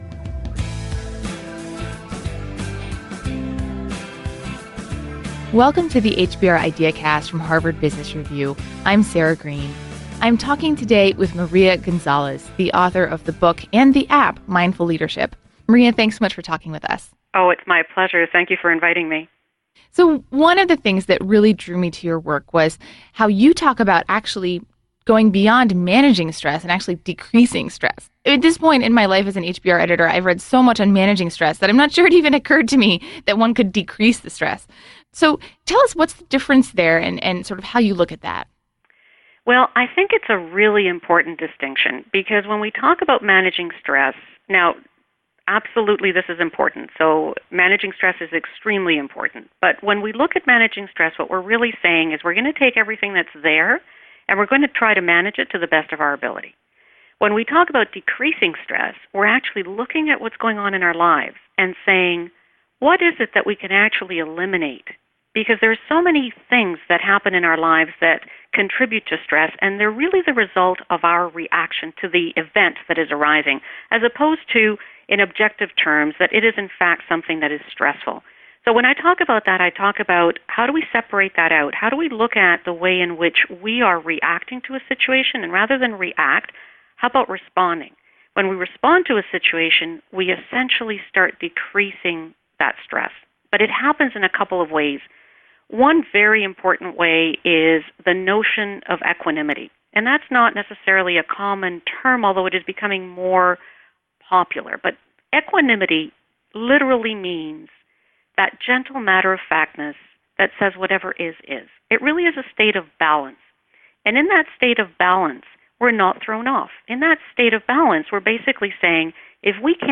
Harvard Business Review IdeaCast Interview: Reduce Stress with Mindfulness
Contains a brief guided breathing exercise.